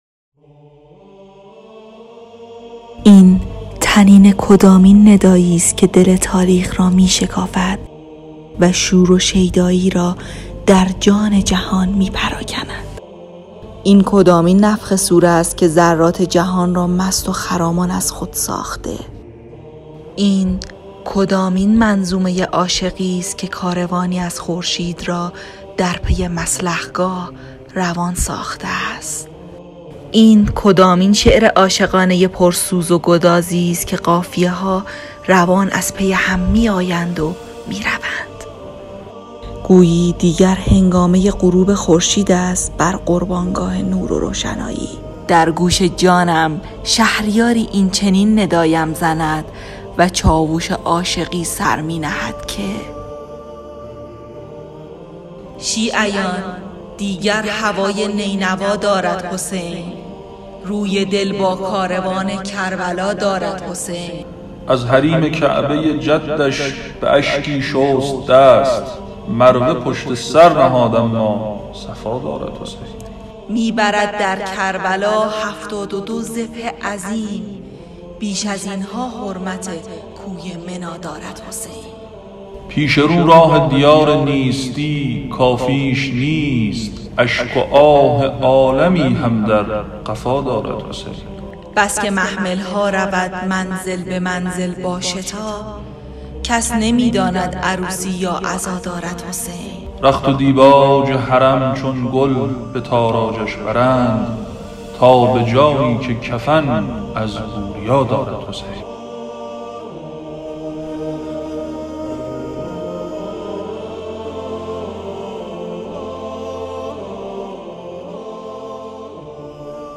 ایکنا در ایام سوگواری حضرت اباعبدالله حسین(ع)، مجموعه پادکست ادبی «با کاروان شهادت» را با موضوع کاروان امام حسین‌(ع) تولید و سومین قسمت این مجموعه را تقدیم نگاه مخاطبان گرامی می‌کند.